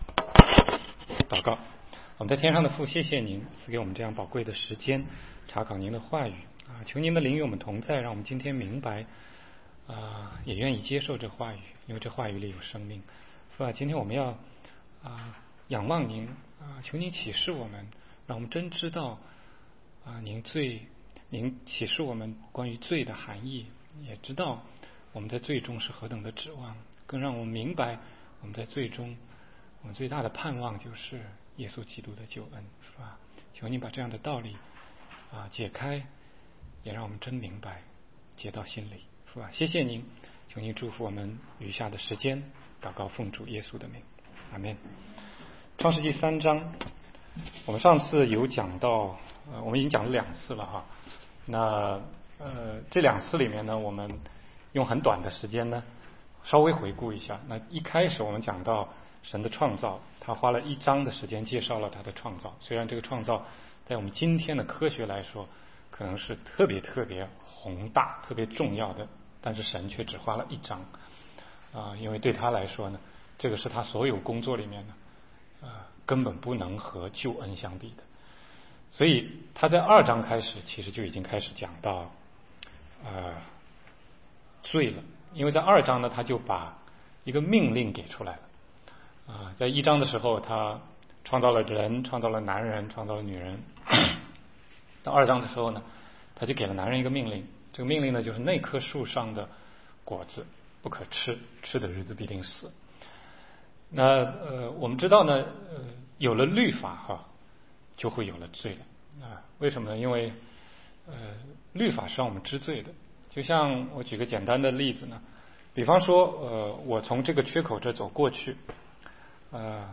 16街讲道录音 - 罪的后果：咒诅还是祝福？